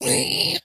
zpighurt1.mp3